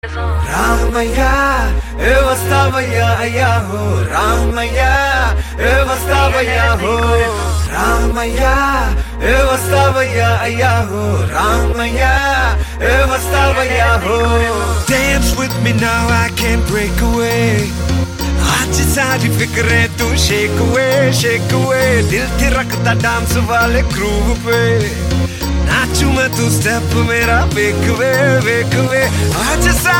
energetic and trending
best flute ringtone download
dance ringtone download